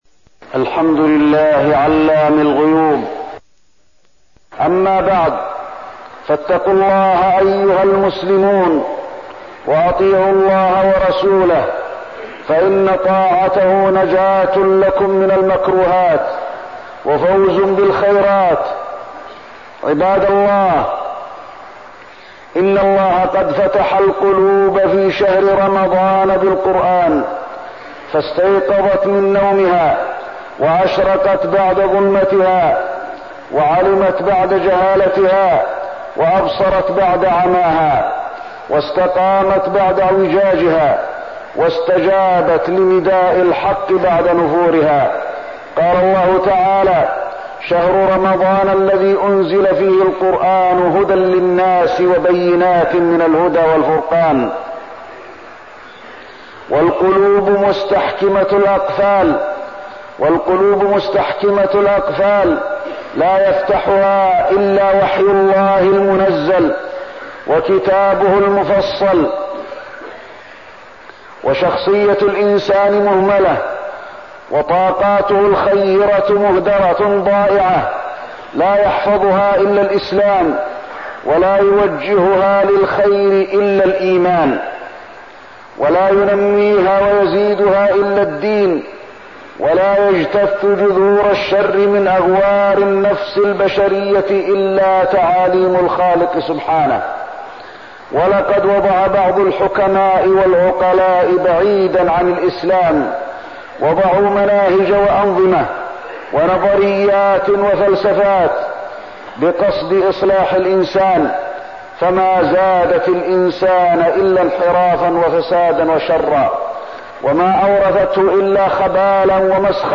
تاريخ النشر ٢٢ رمضان ١٤١٤ هـ المكان: المسجد النبوي الشيخ: فضيلة الشيخ د. علي بن عبدالرحمن الحذيفي فضيلة الشيخ د. علي بن عبدالرحمن الحذيفي العشر الأواخر من رمضان